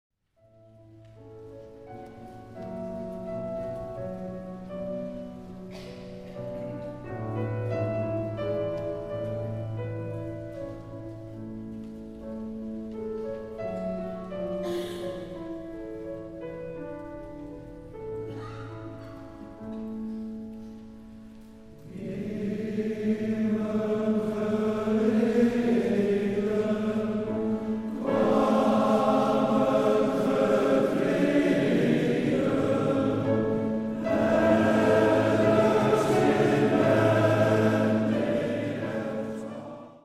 Soester Mannenkoor Apollo